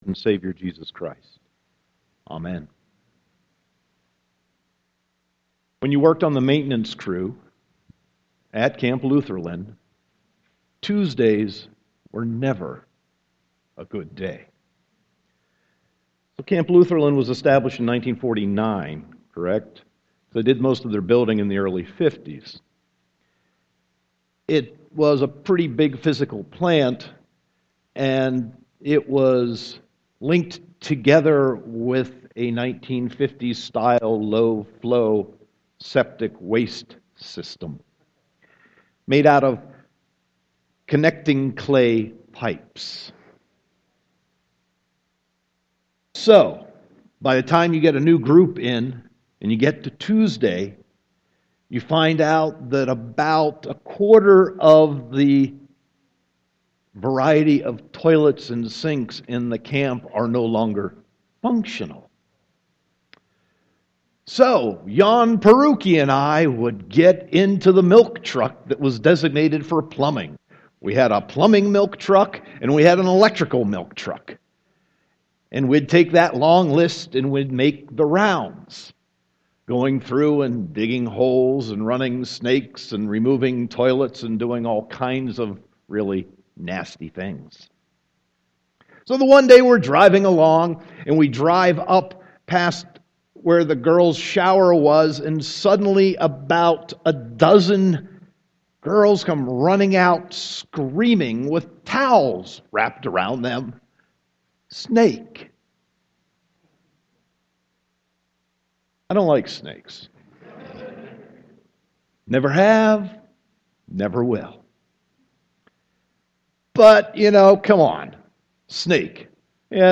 Sermon 3.15.2015
*There is technical difficulty within this sermon.